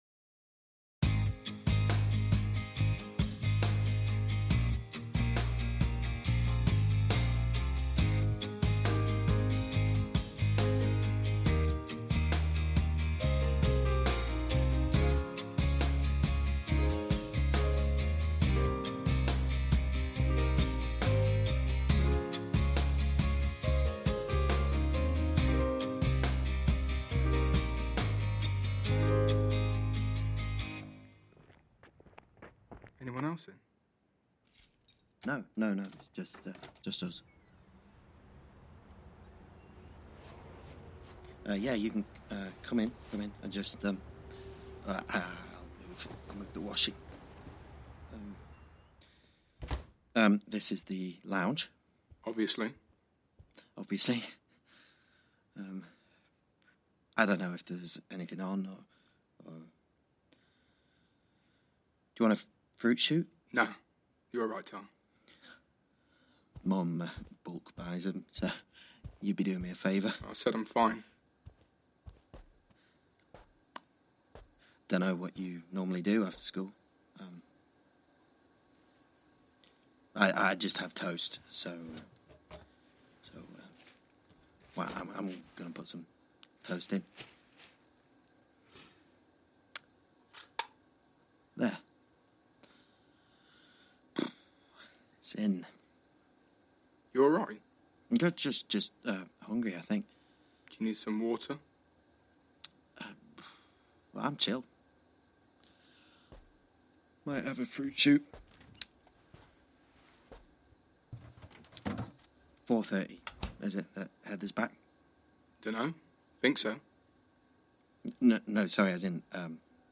A new audio-play